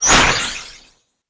sfx_skill 02.wav